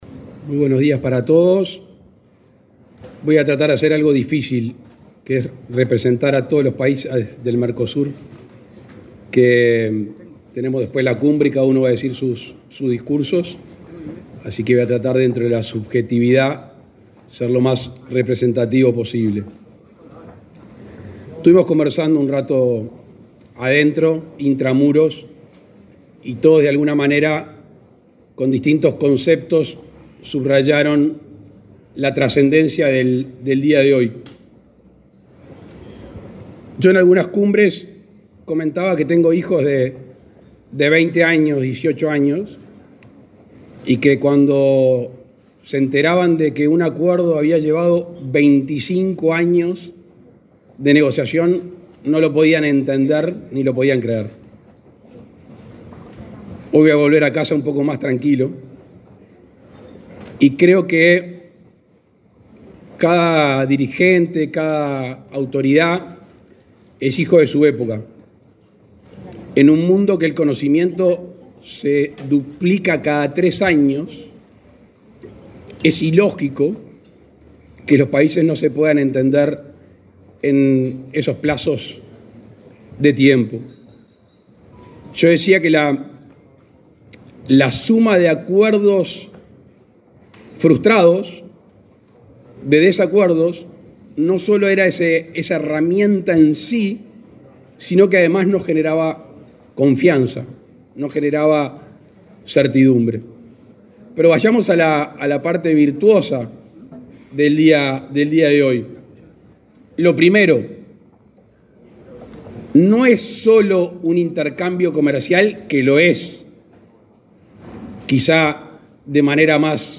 Palabras del presidente Luis Lacalle Pou